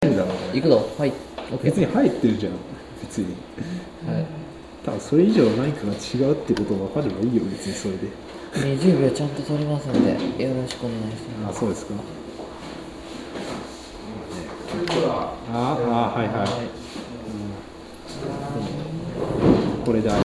今日は部室で
感度が良すぎると周りの音をかなり録ってしまうってことだけは言える、うん。